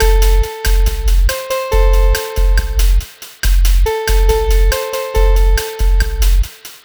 Cheese Lik 140-A.wav